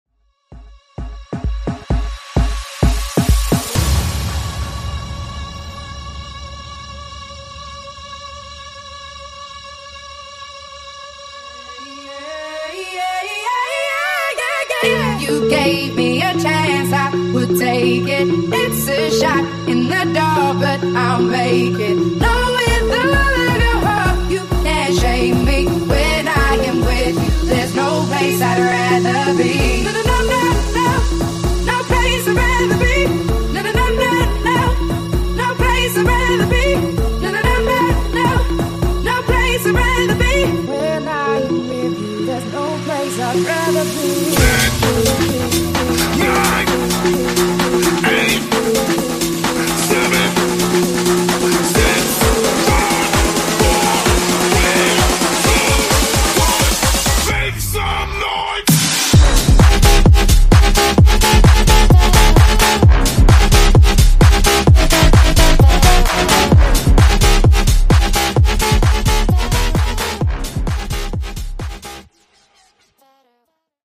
Genres: R & B , RE-DRUM
Clean BPM: 100 Time